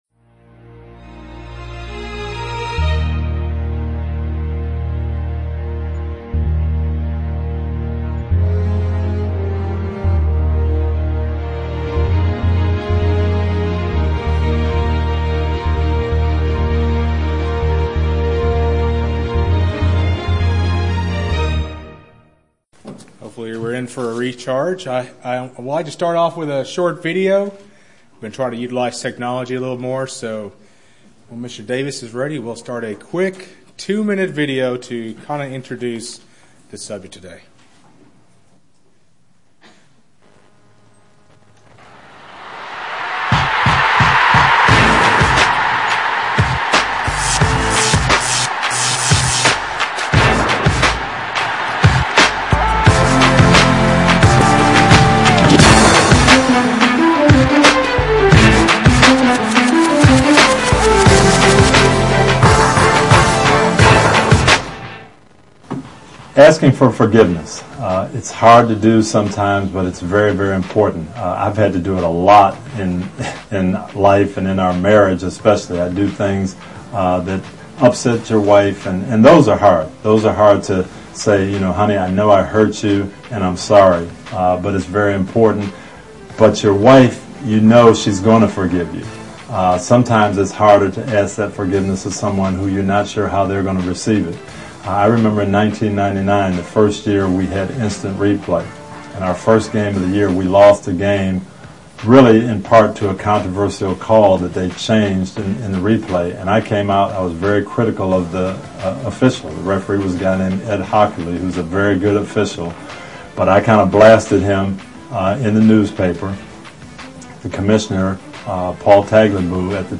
This is the second split sermon in a series on forgiveness